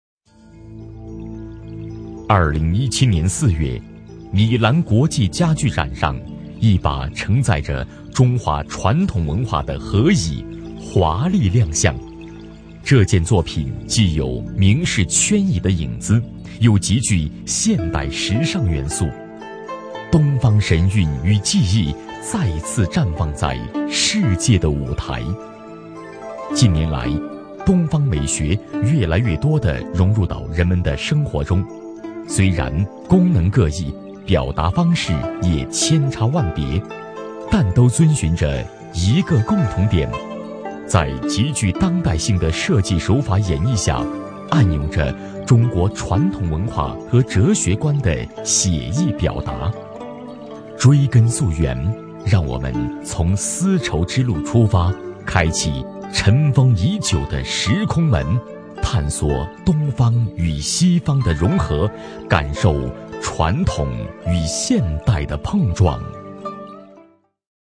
专题男配
【男8号专题】浑厚历史家具